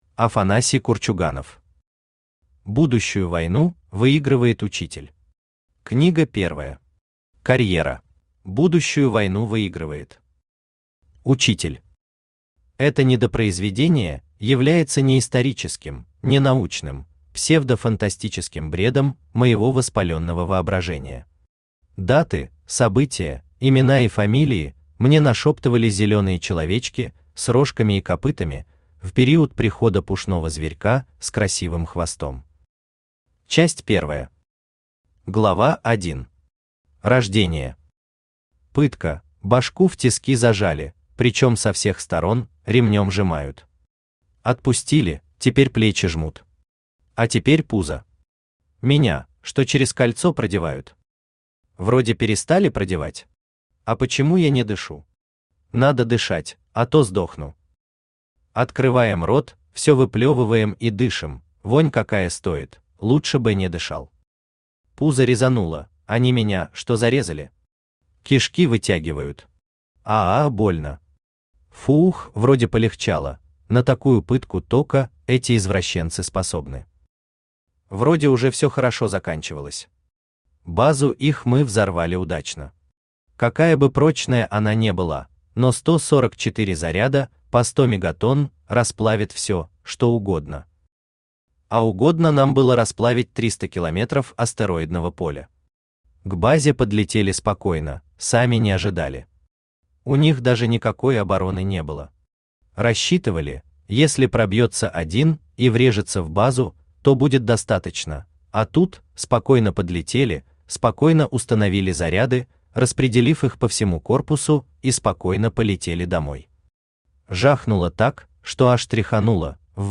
Аудиокнига Будущую войну выигрывает учитель. Книга первая. Карьера | Библиотека аудиокниг
Aудиокнига Будущую войну выигрывает учитель. Книга первая. Карьера Автор Афанасий Иванович Курчуганов Читает аудиокнигу Авточтец ЛитРес.